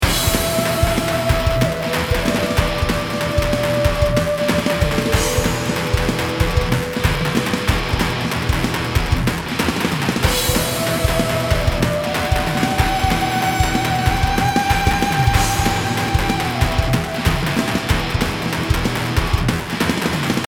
BPM 188